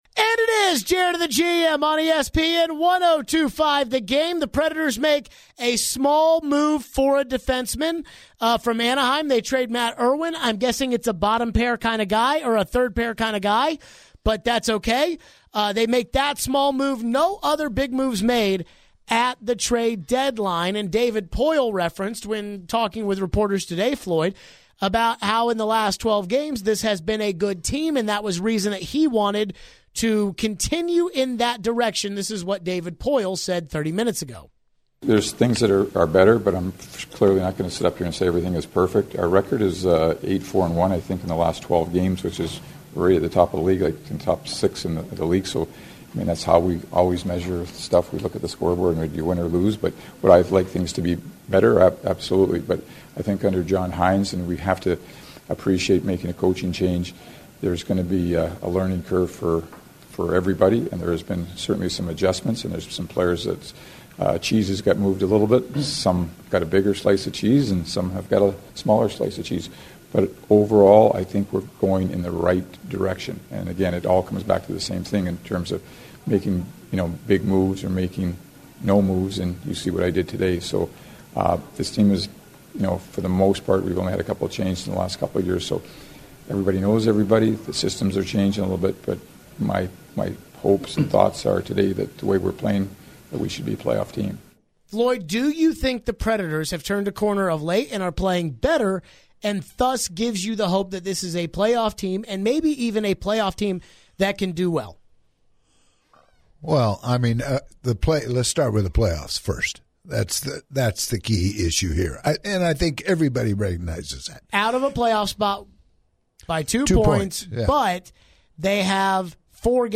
Preds Deadline and Phone calls and Texts